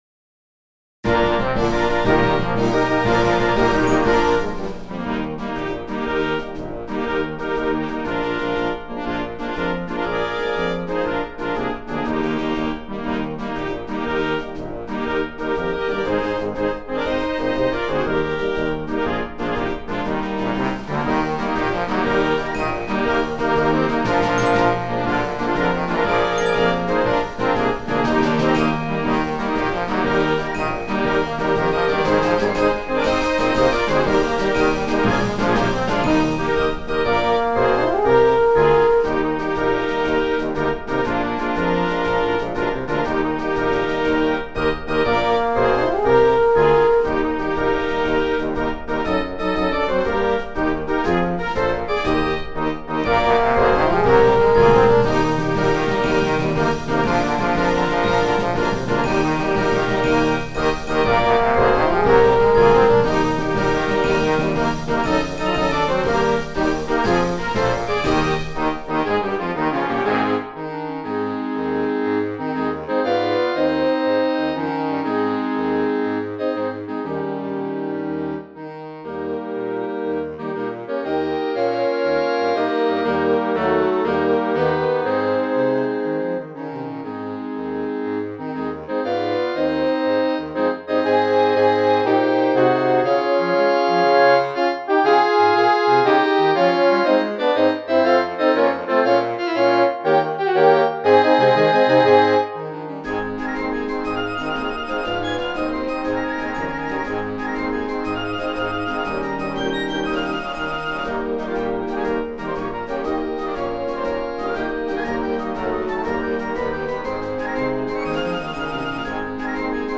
This is a better recording of this march than was posted earlier.
He was a woodwind player, so I featured the WW section with the horns getting a little honorarium.
MARCH MUSIC; MILITARY MUSIC